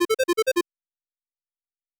Find_Item.wav